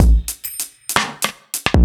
OTG_Kit6_Wonk_130a.wav